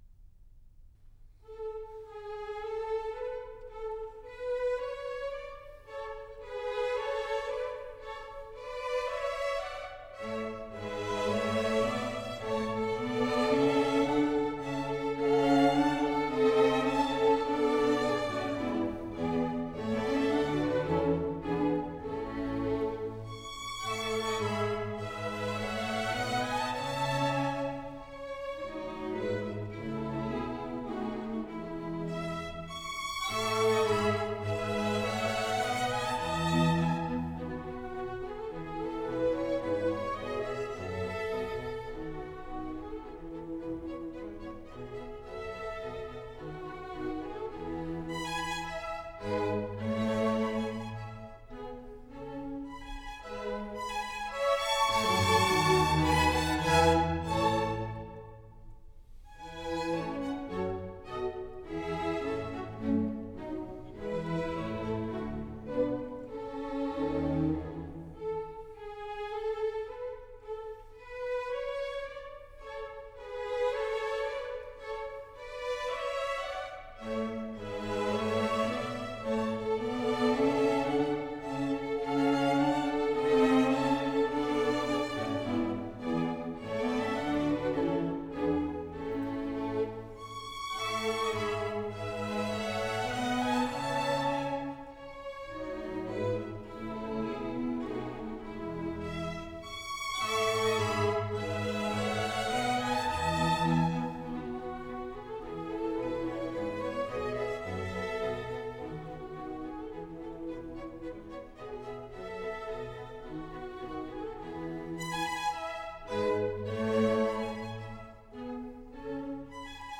» nhac-khong-loi
» 1 - Symphonies